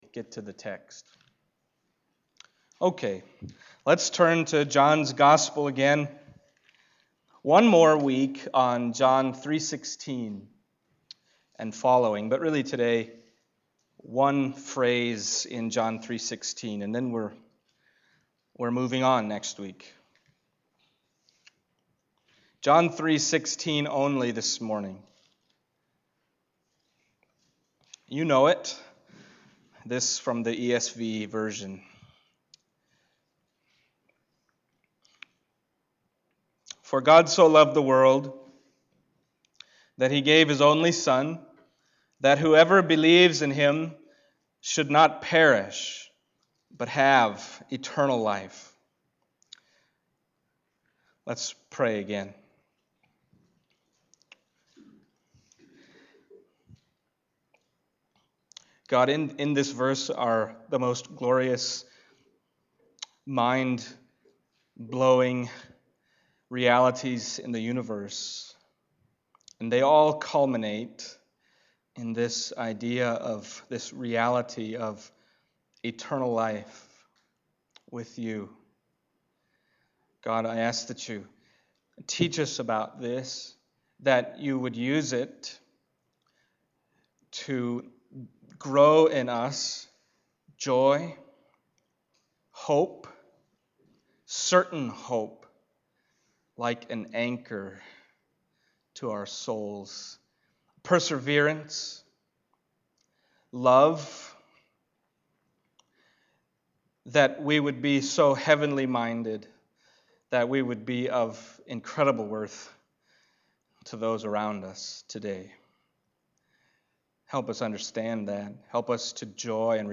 John Passage: John 3:1-21 Service Type: Sunday Morning John 3:1-21 « Not All Believe in God’s Only Son …